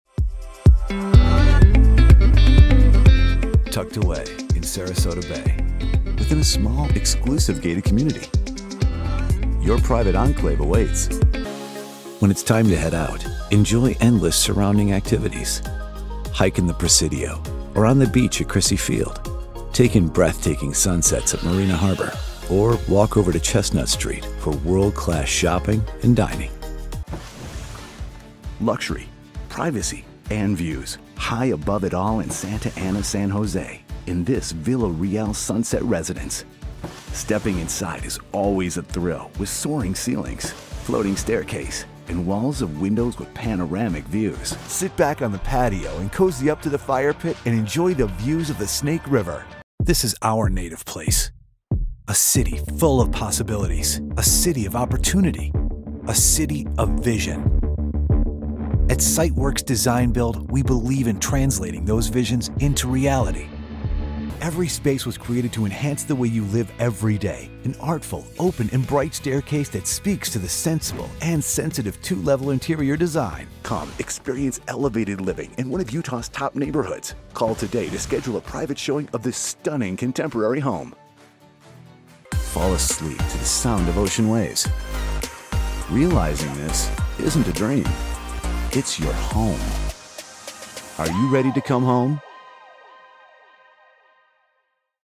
Male
Adult (30-50), Older Sound (50+)
Corporate
Corporate Video Voiceovers